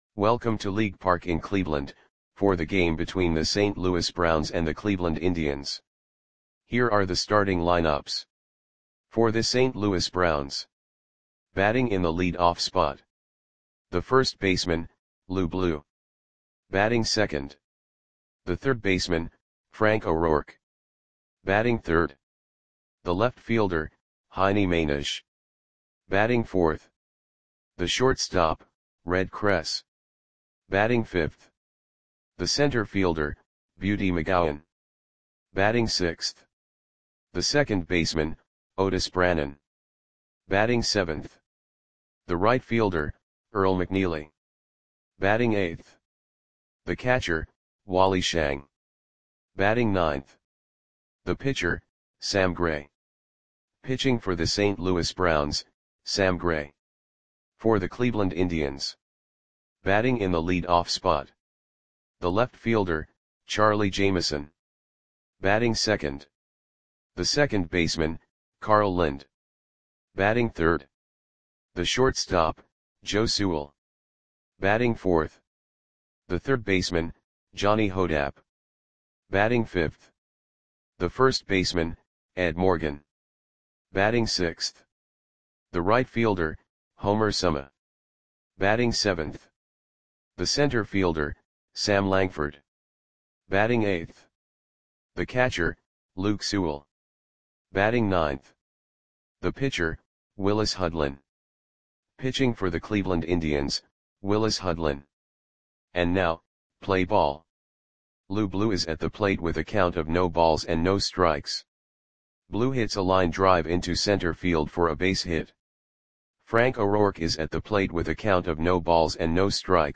Audio Play-by-Play for Cleveland Indians on July 22, 1928
Click the button below to listen to the audio play-by-play.